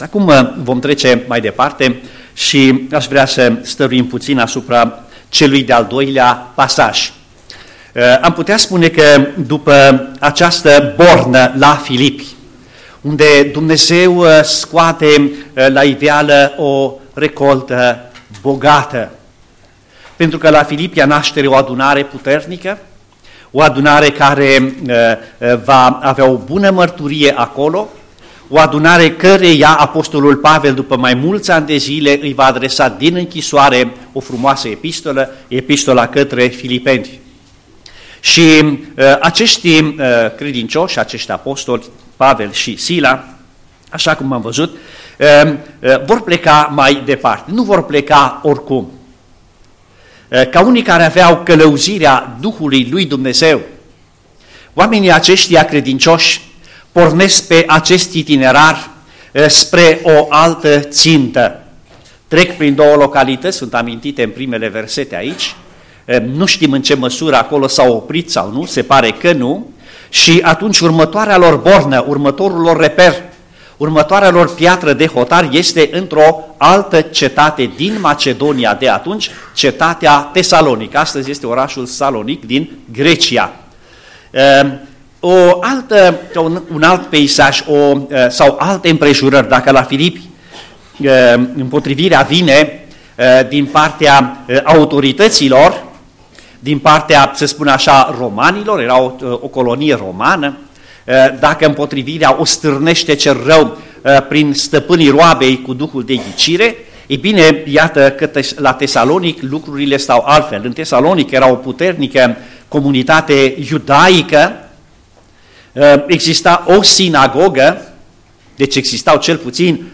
Tineret, seara Predică